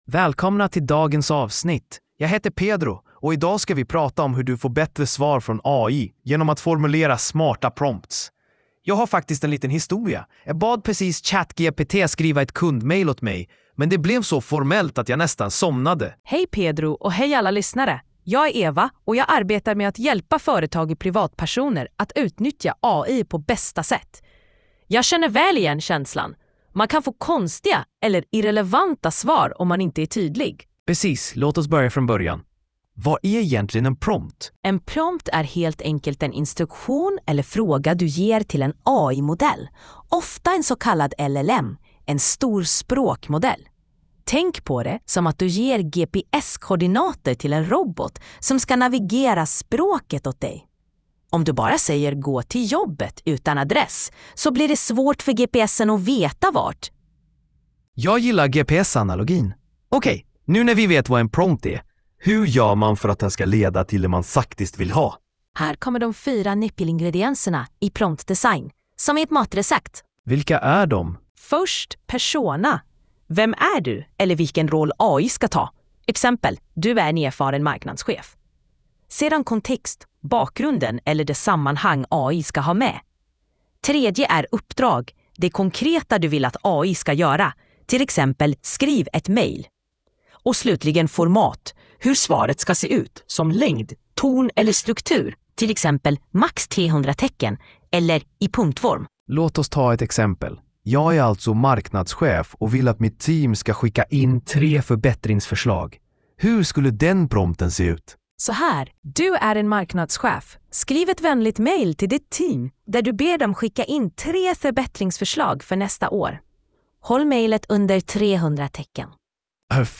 Lyssna på avsnittet genom en AI-genererad podcast: